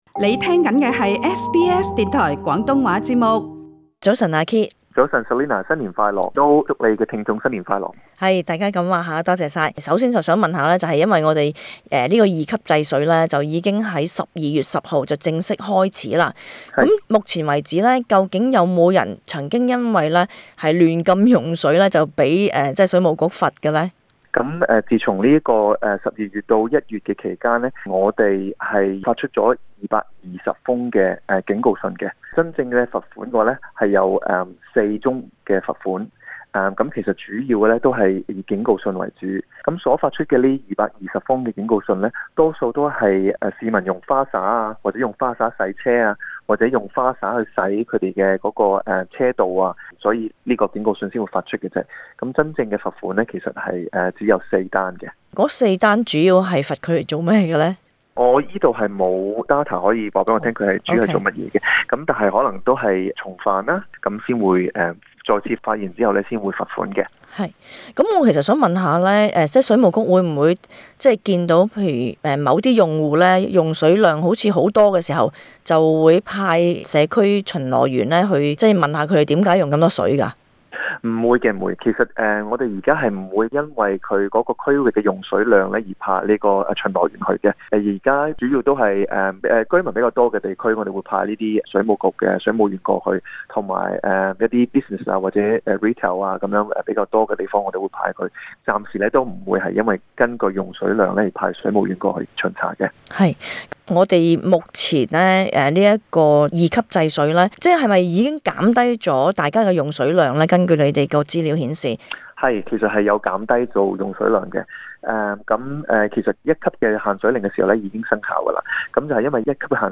【社區專訪】